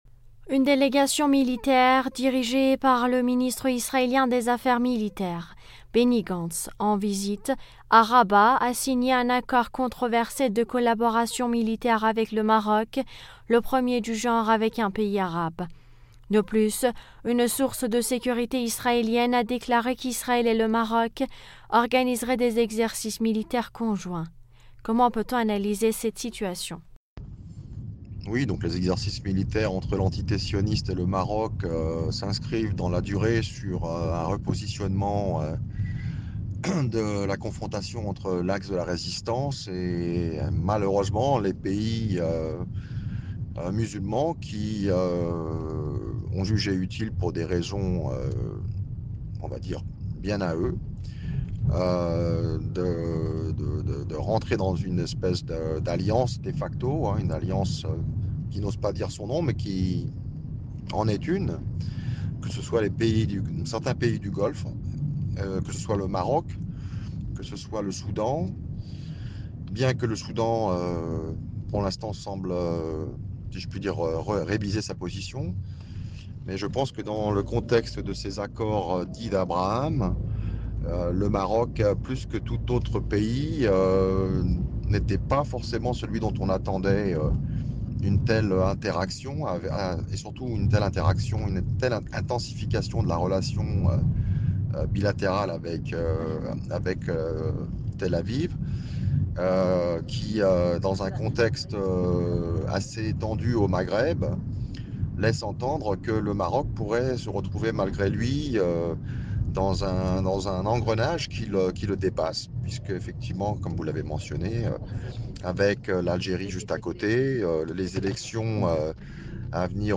Mots clés Afrique Israël interview Eléments connexes Pourquoi Israël craint-il l’exercice naval conjoint de l’Égypte et de la Turquie ?